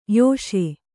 ♪ yōṣe